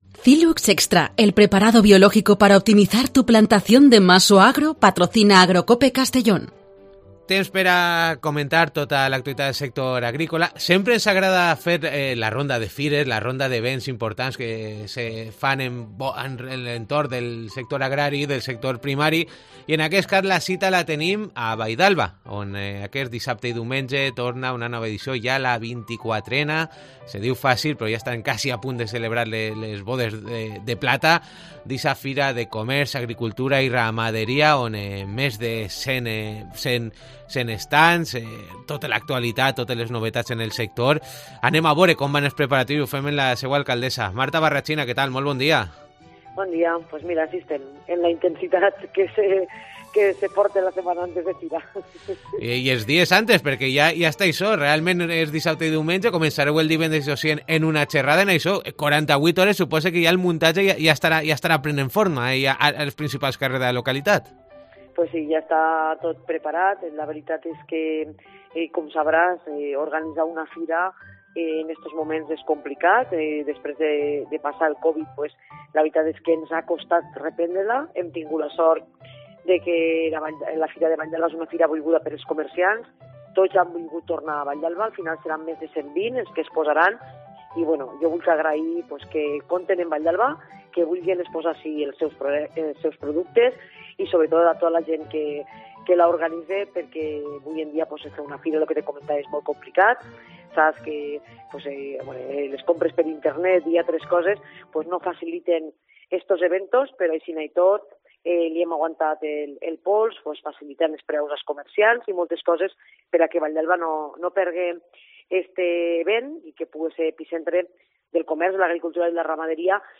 Hoy con Marta Barrachina desde el ayuntamiento de la Vall d'Alba y la actualidad del sector.